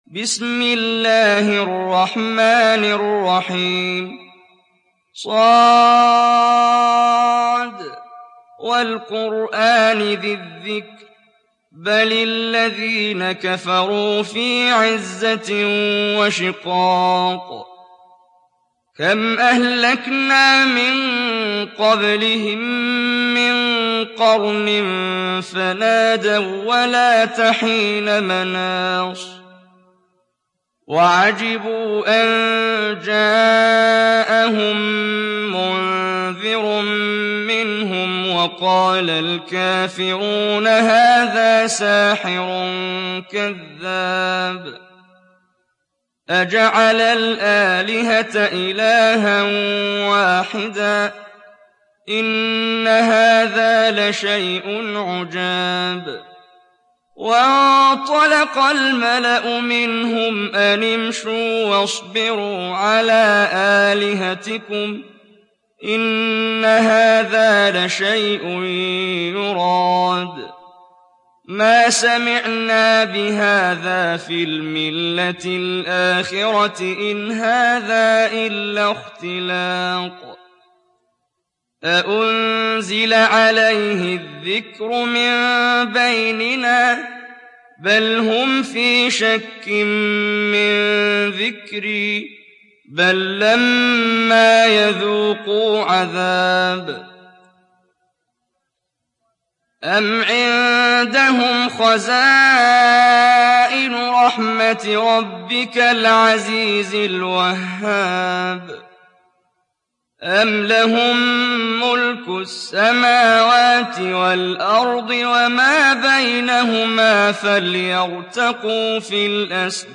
تحميل سورة ص mp3 بصوت محمد جبريل برواية حفص عن عاصم, تحميل استماع القرآن الكريم على الجوال mp3 كاملا بروابط مباشرة وسريعة